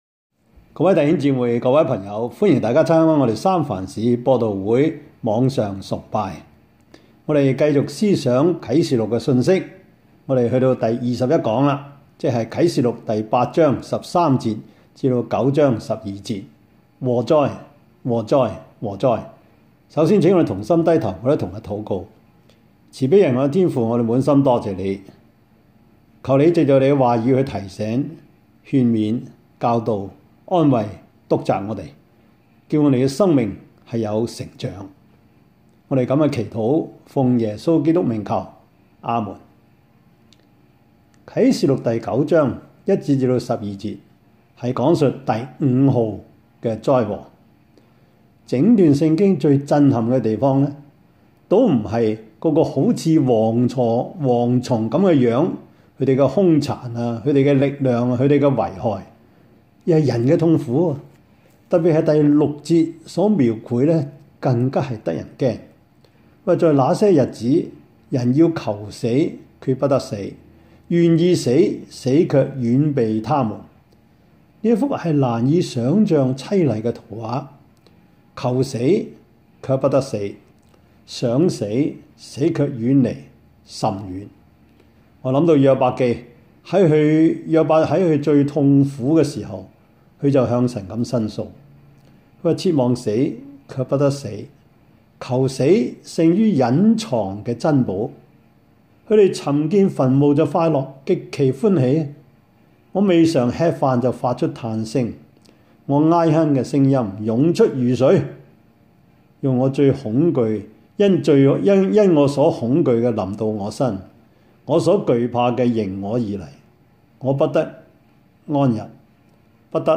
Topics: 主日證道 « 第二十二課:聖法蘭西(St. Francis of Assisi) 風浪中的那一位到底是誰?